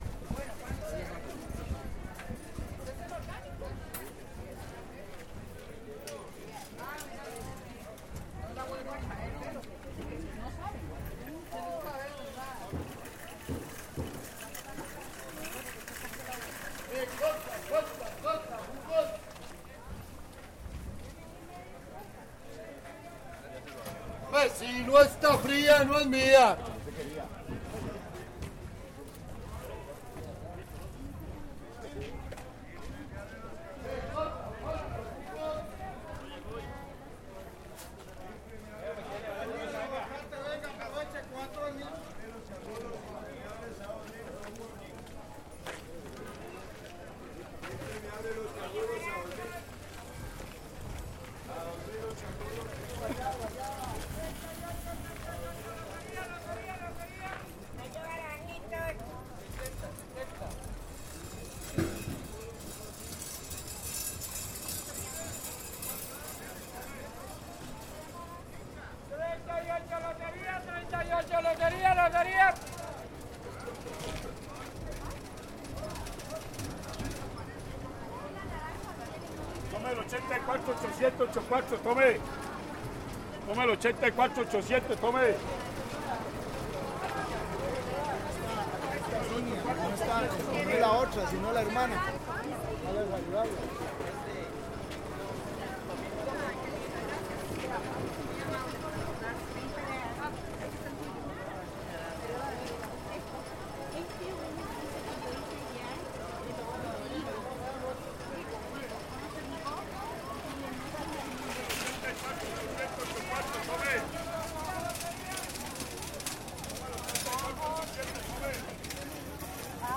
(MONO) Caminata en Feria de Plaza Viquez ACM 2018
dc.subjectPREGONEOes_ES
dc.subjectCONVERSACIÓNes_ES
dcterms.audio.microphoneZoom H6 con la cápsula MS y Rode NTG3 con la caña y el blimp. Ambos con windscreen.es_ES
(MONO) Caminata en Feria de Plaza Viquez ACM 2018.mp3